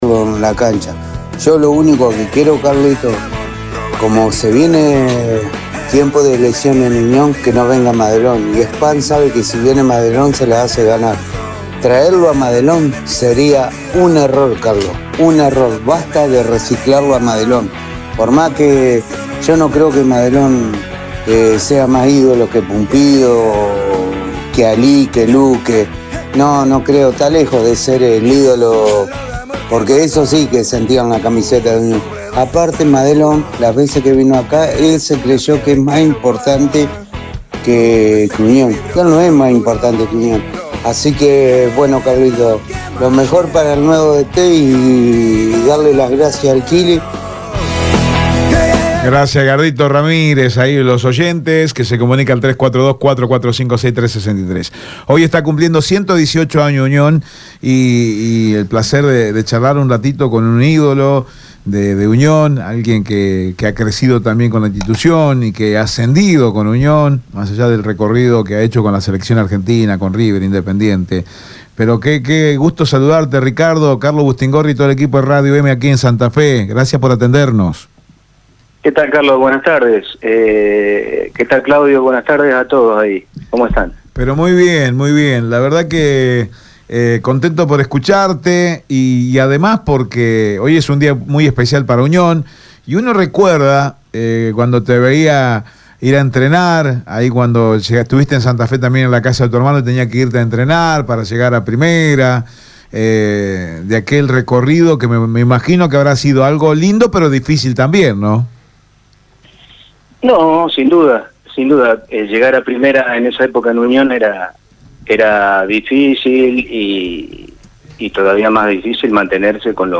En el día del cumpleaños 118 del Club Atlético Unión hoy dialogamos en EME Deportivo con un ícono futbolístico: Ricardo Altamirano.